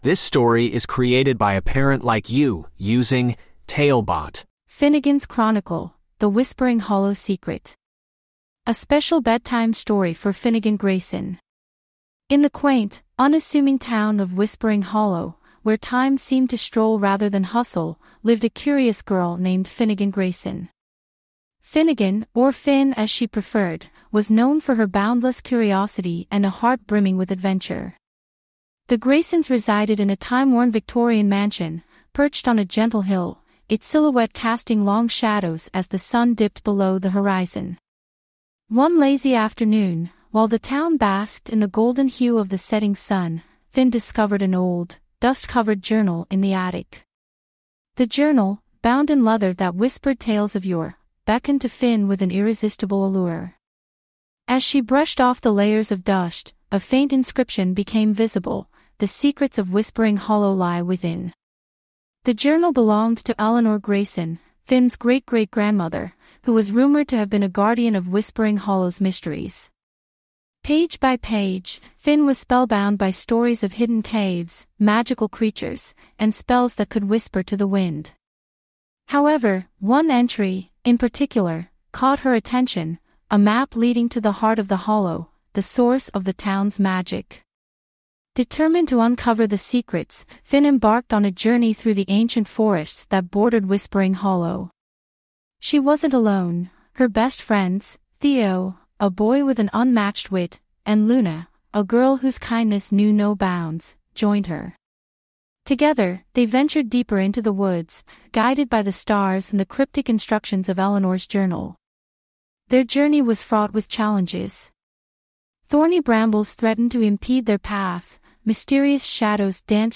TaleBot Bedtime Stories
TaleBot AI Storyteller